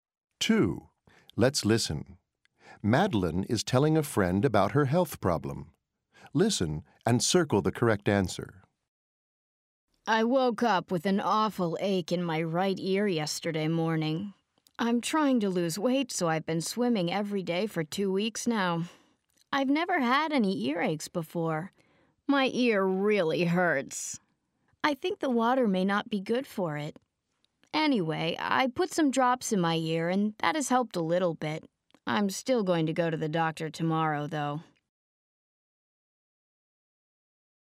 Madeleine is telling a friend about her health problem.